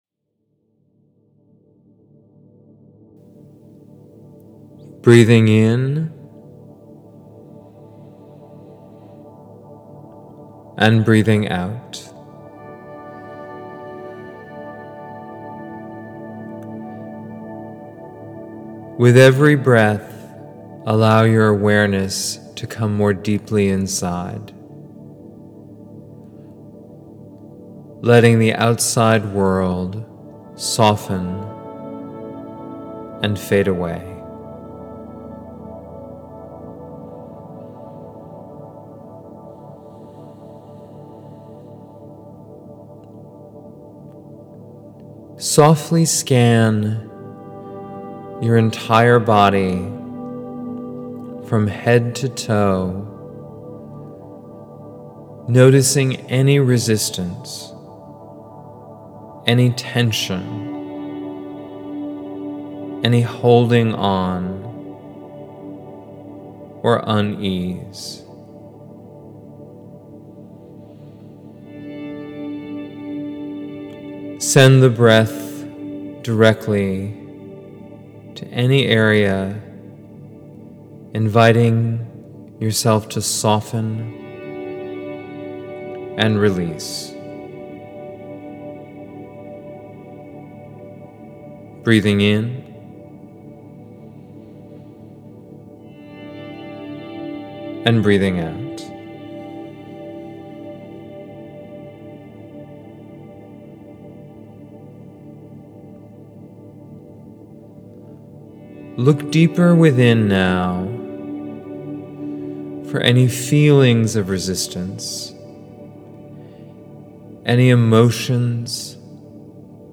Resistance-Meditation.mp3